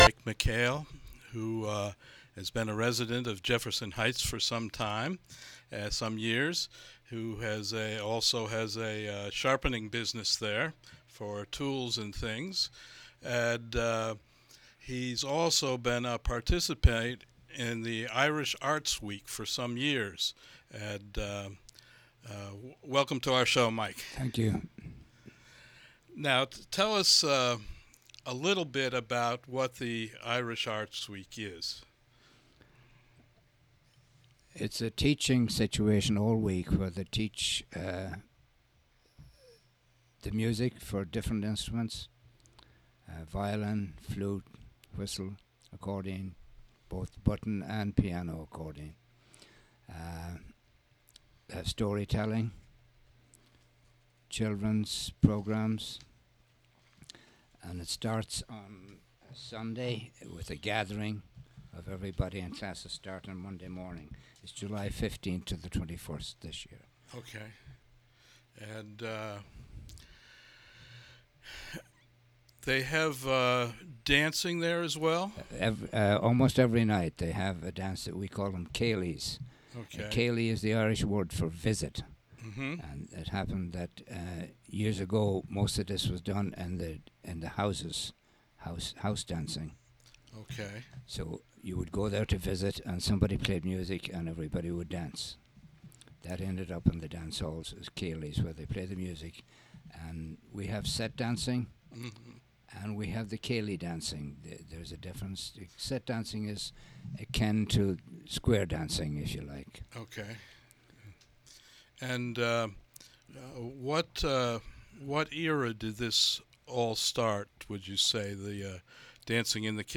Interview
On "WGXC Afternoon Show" from Catskill Community Center.